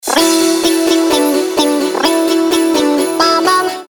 • Качество: 320, Stereo
веселые
короткие
рождественские
Прикольное новогоднее сообщение